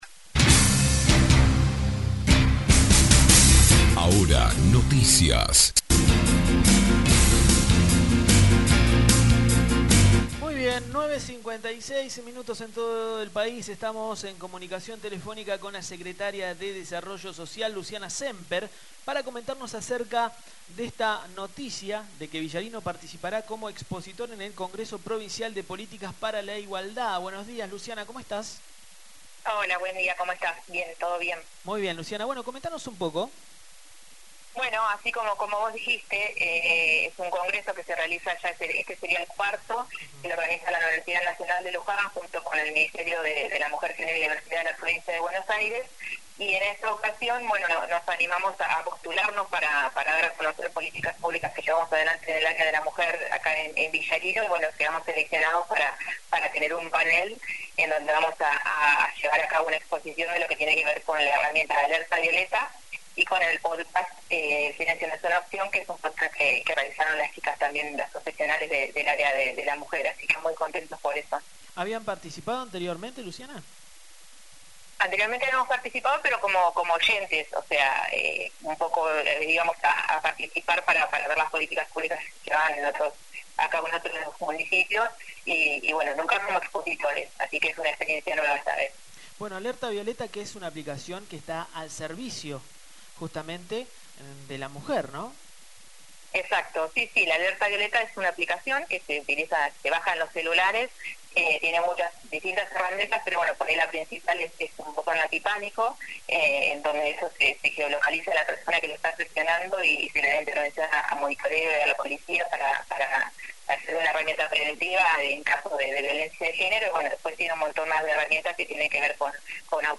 La Secretaria de Desarrollo Social de Villarino Luciana Semper nos comentó que Villarino participará en el Cuarto Congreso Provincial de Políticas para la Igualdad.